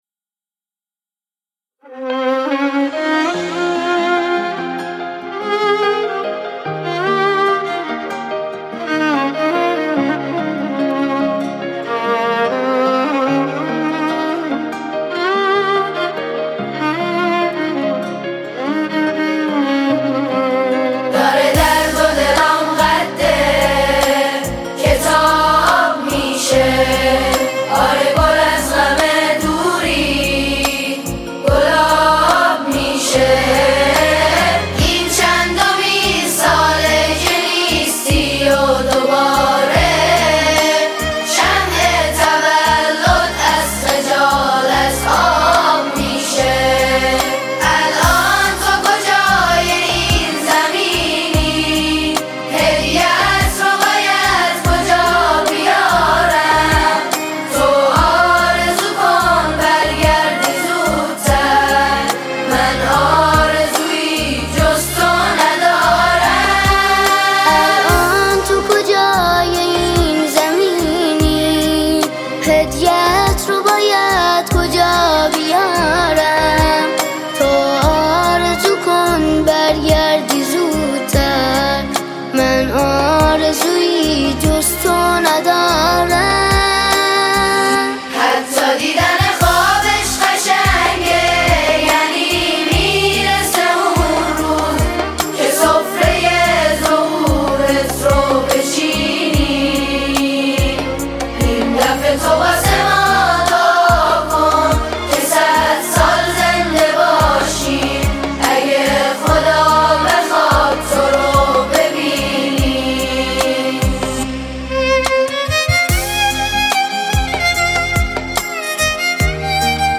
ژانر: سرود ، سرود مذهبی ، سرود مناسبتی